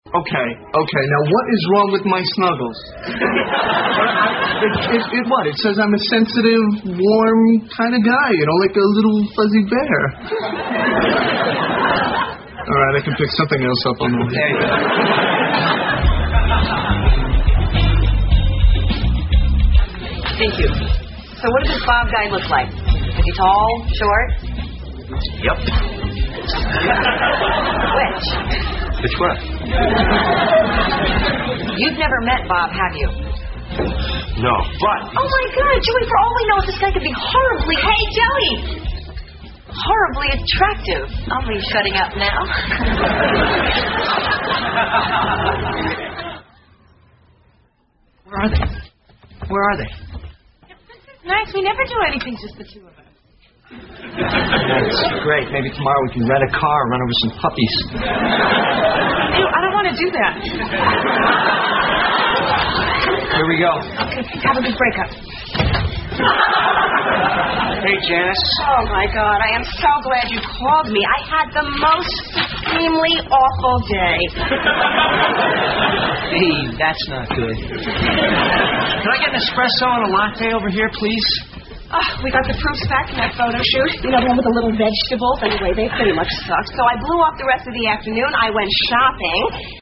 在线英语听力室老友记精校版第1季 第52期:洗衣服(5)的听力文件下载, 《老友记精校版》是美国乃至全世界最受欢迎的情景喜剧，一共拍摄了10季，以其幽默的对白和与现实生活的贴近吸引了无数的观众，精校版栏目搭配高音质音频与同步双语字幕，是练习提升英语听力水平，积累英语知识的好帮手。